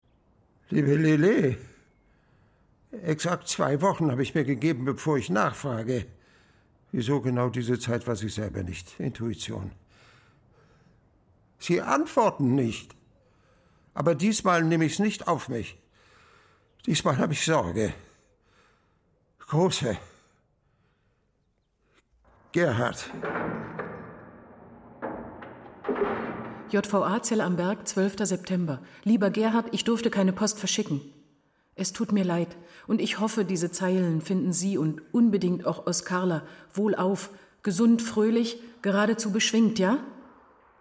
Matthias Habich spielt Gerhard, den ehemaligen Lehrer und Witwer, mit einer guten Portion Kauzigkeit und Selbstironie. Julia Jäger spricht samtig und lässt klar die Empfindsamkeit ahnen, die unter einer harten Schale liegt.